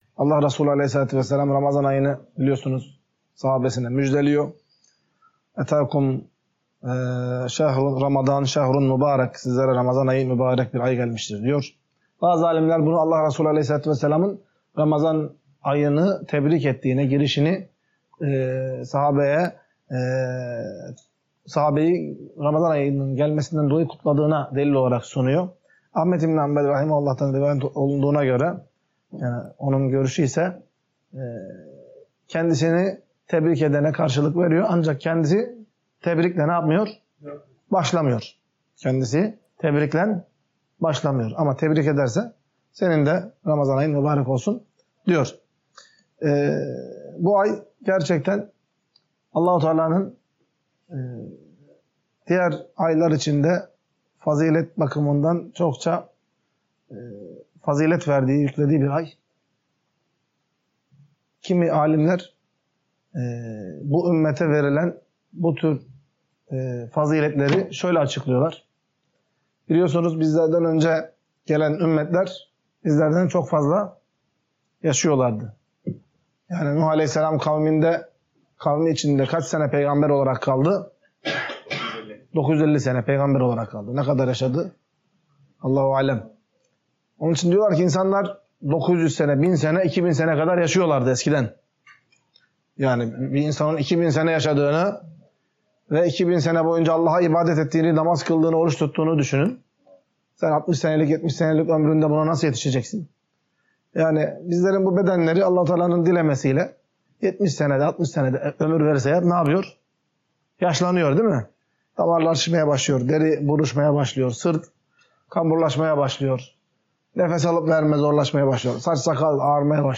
Ders - 42.